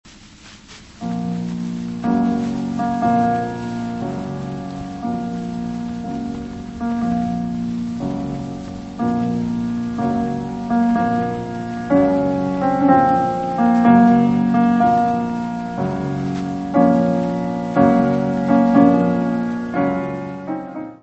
piano.
Área:  Música Clássica